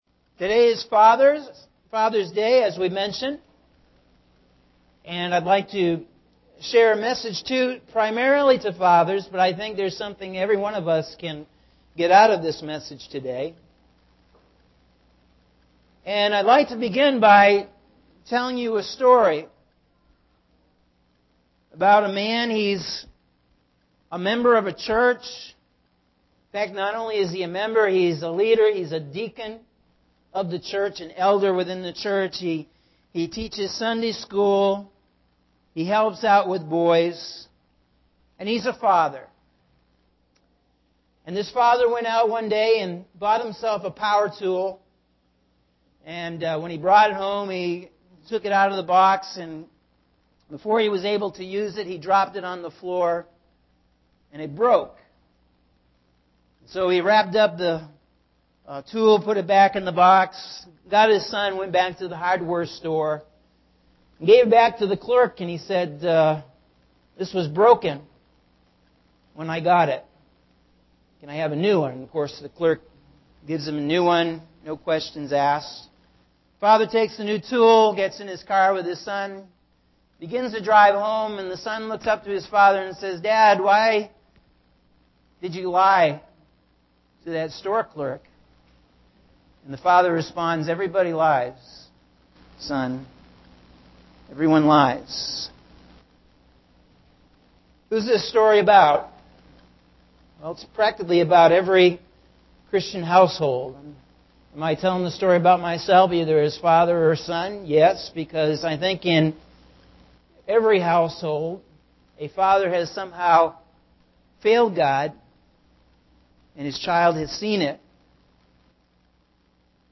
Sunday June 16 – AM Sermon – Norwich Assembly of God